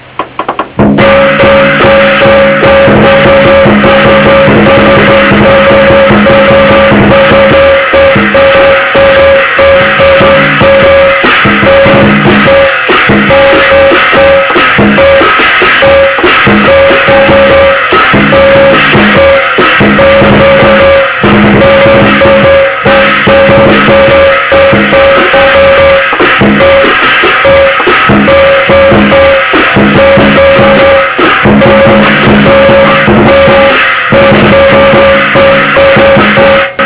danza_del_leon_reducida.wav